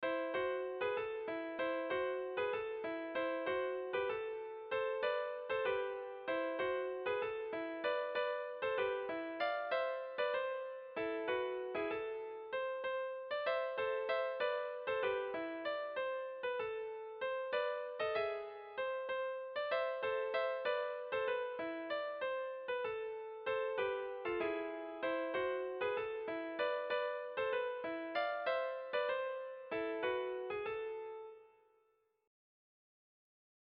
Erlijiozkoa
Hamarreko handia (hg) / Bost puntuko handia (ip)
A1A2B1B2A2